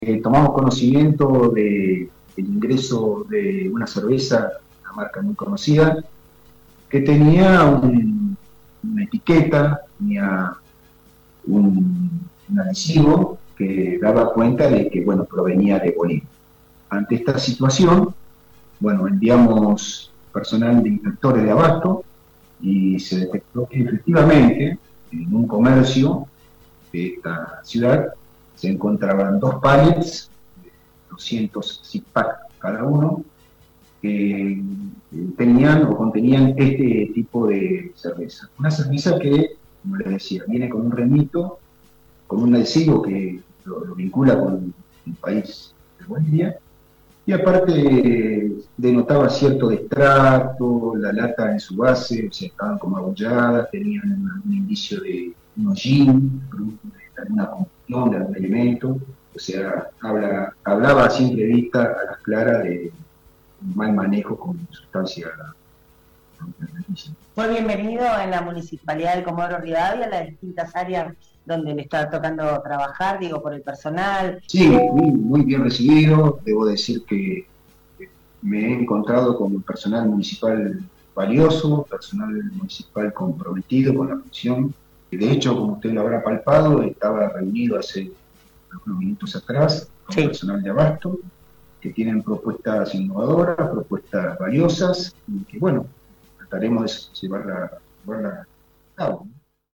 El recientemente nombrado a cargo de esa secretaría Miguel Gómez, en diálogo con LA MAÑANA DE HOY, nos contó cómo fue el operativo: